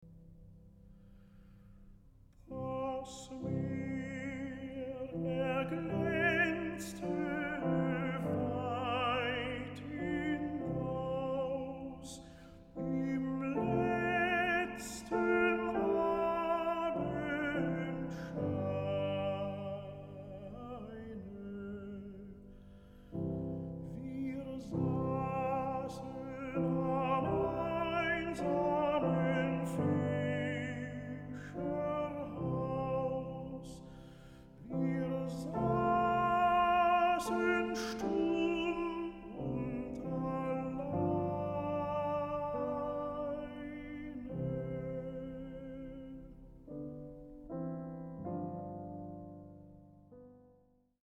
Klavier/piano